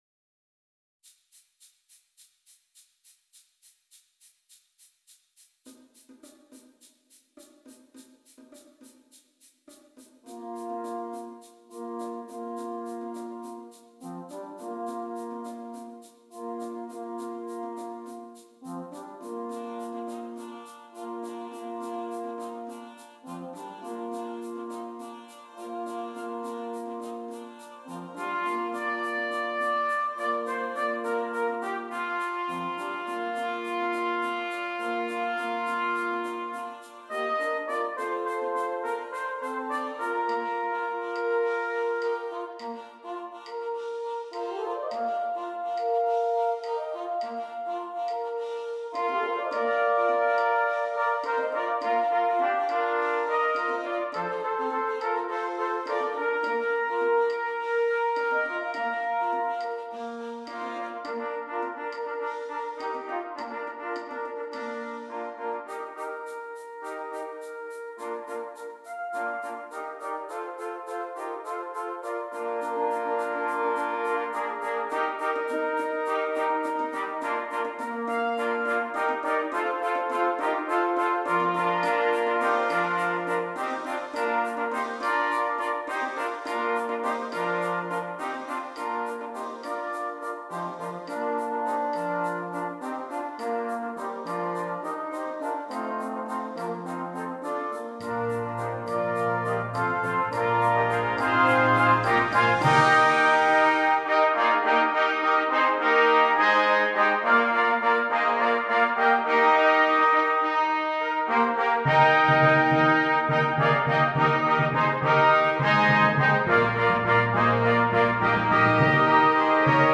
8 Trumpets and Percussion
Traditional